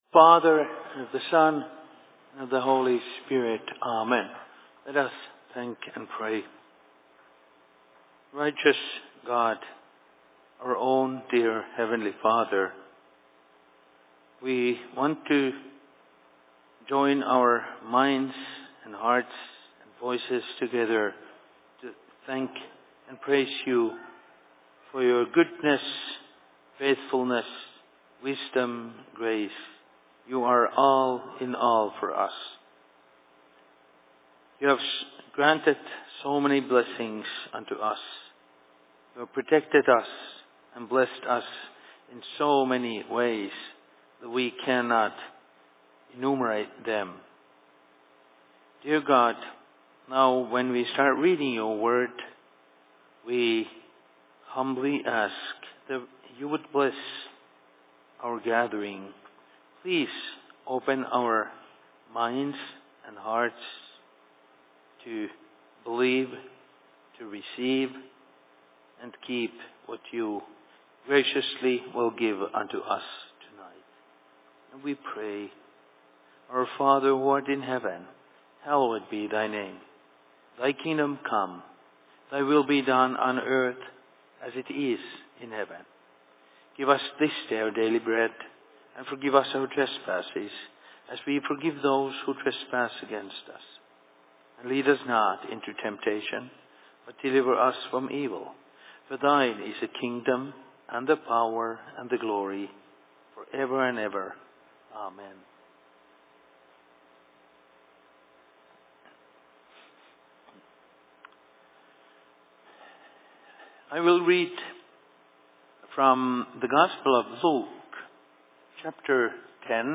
Services/Sermon in Rockford 19.08.2018
Location: LLC Rockford